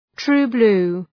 Προφορά
{,tru:’blu:}